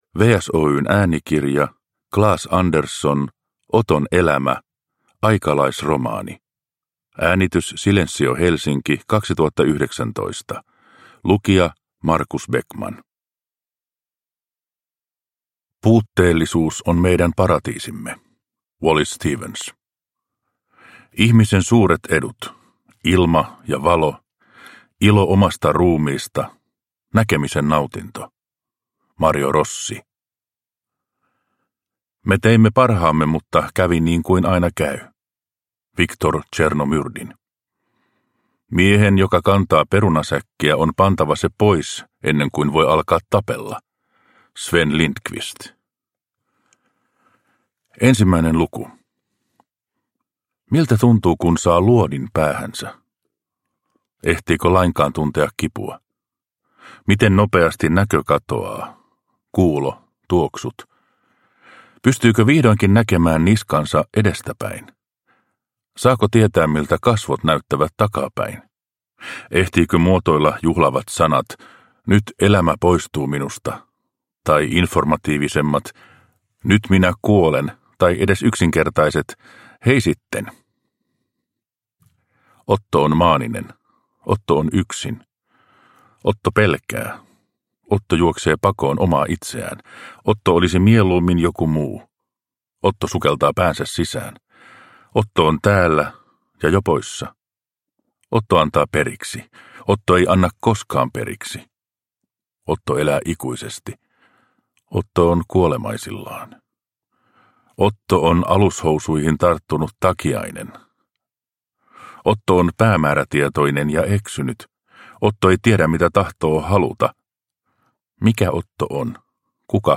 Oton elämä (ljudbok) av Claes Andersson